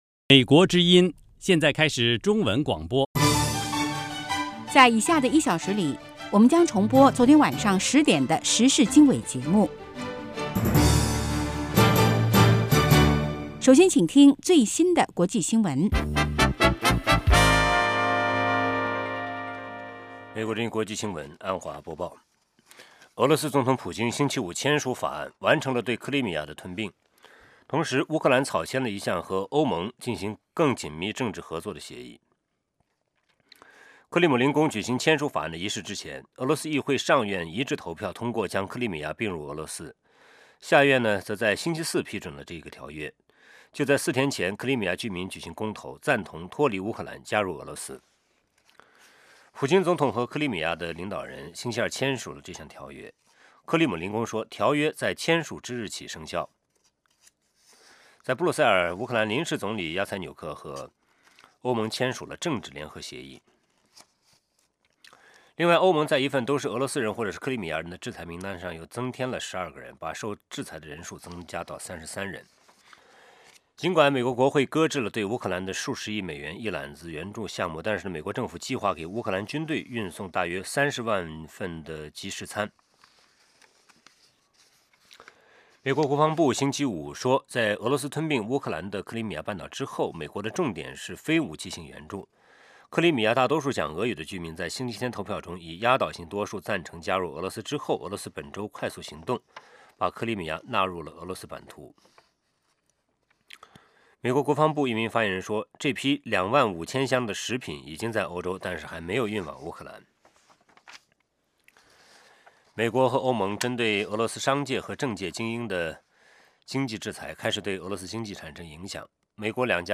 国际新闻 时事经纬(重播) 北京时间: 上午6点 格林威治标准时间: 2200 节目长度 : 60 收听: mp3